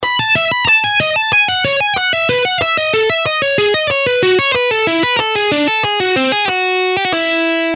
By combining the use of the pick and fingers, hybrid picking enables players to achieve a rich and dynamic sound that can elevate their playing to new heights.
Hybrid-Picking-Exercise-5.mp3